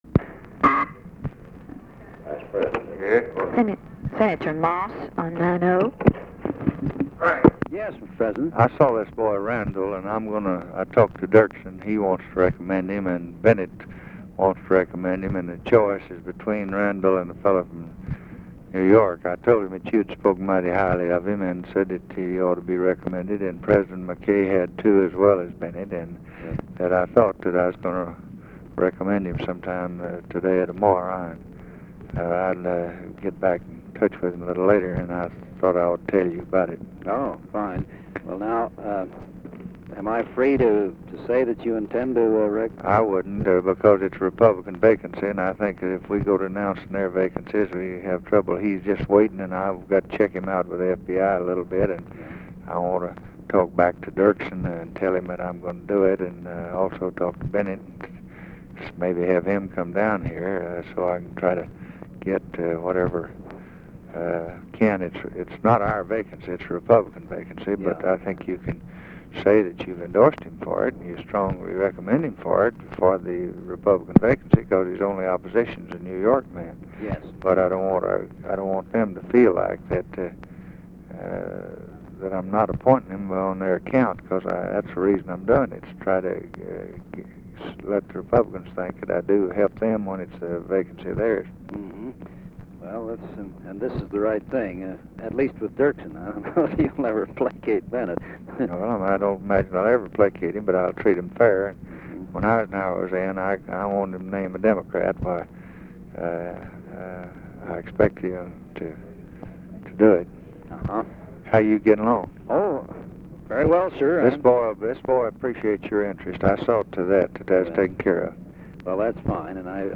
Conversation with FRANK MOSS, February 5, 1964
Secret White House Tapes